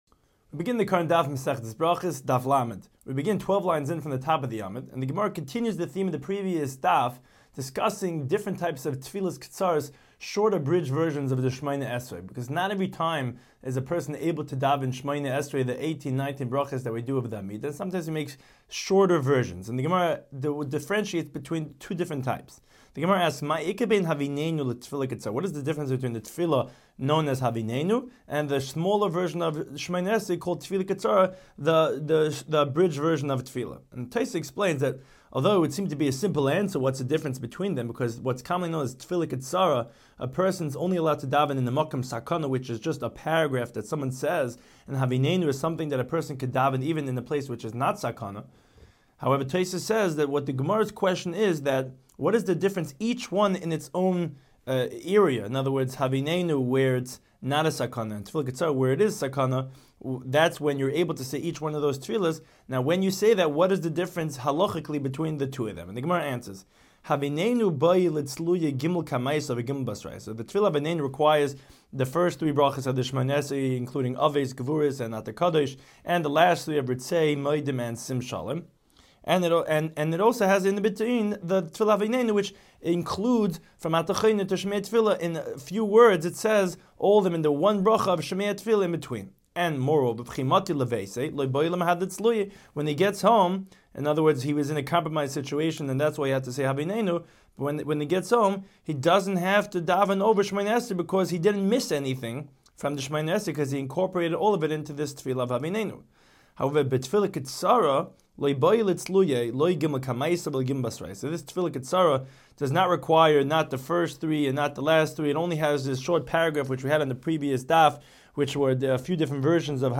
Daf Hachaim Shiur for Berachos 30